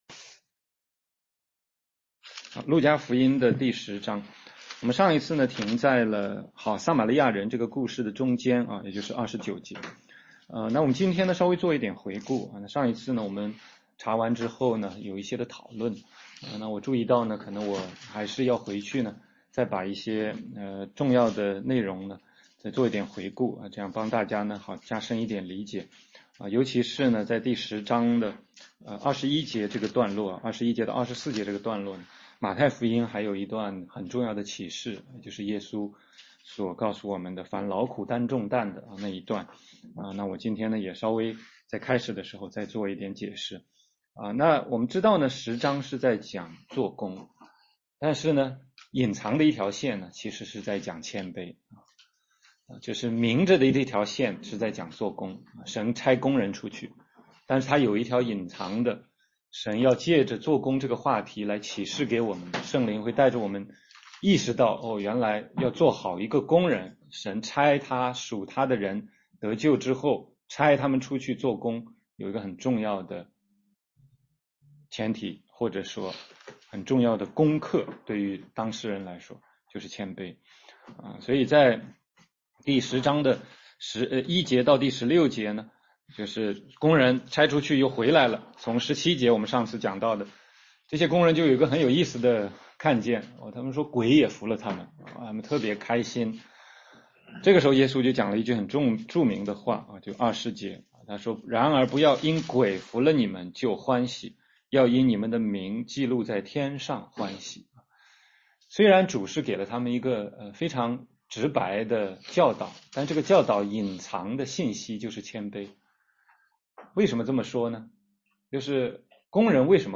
16街讲道录音 - 路加福音10章30-37节：好撒马利亚人的故事